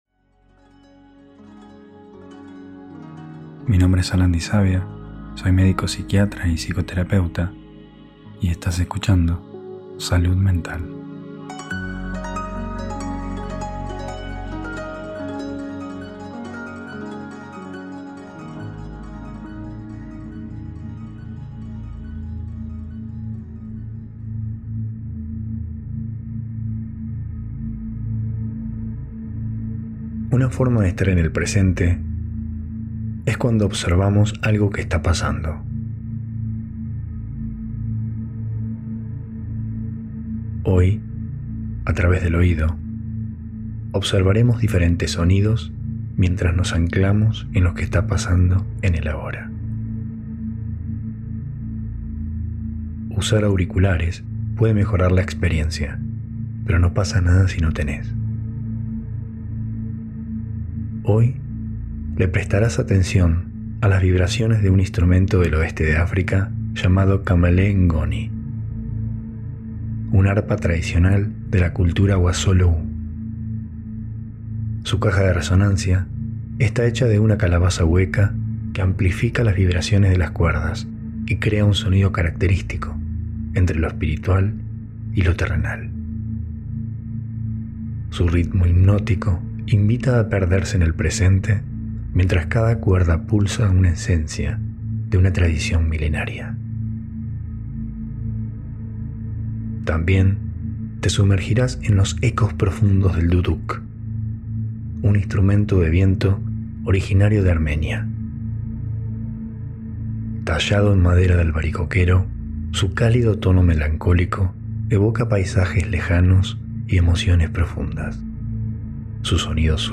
Meditación Sonora para la Presencia ✦ Kamale N’goni y Duduk
En esta meditación, en lugar de enfocarte en la respiración o en los pensamientos, te dejarás guiar por las vibraciones del Kamale N’Goni y el Duduk, dos instrumentos ancestrales que te invitan a estar en el ahora..Para citas y consultas